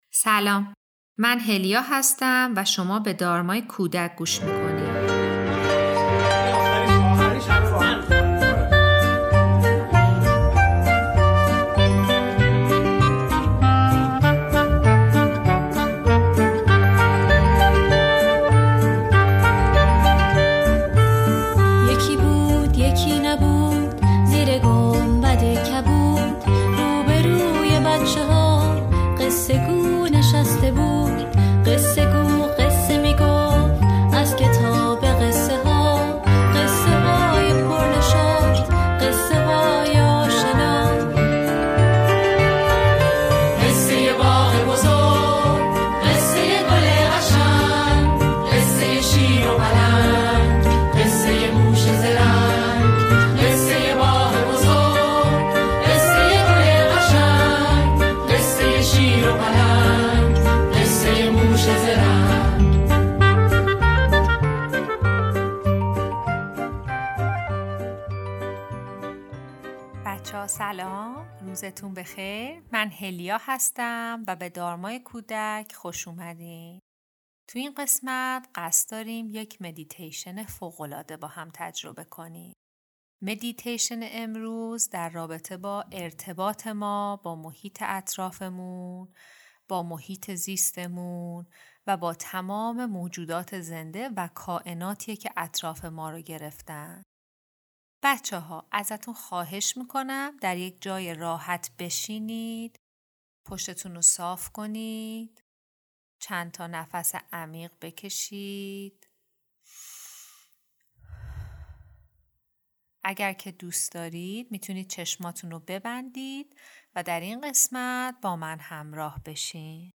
مدیتیشن: ارتباط با طبیعت و جهان هستی